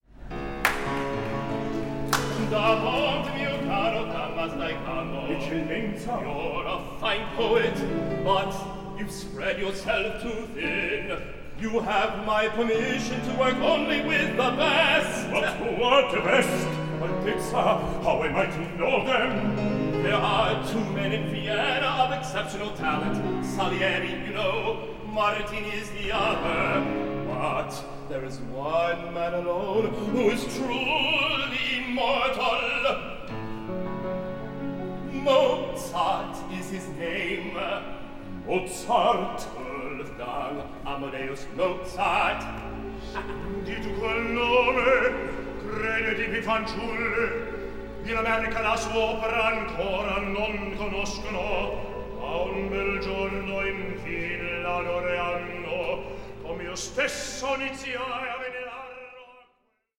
Recitativo secco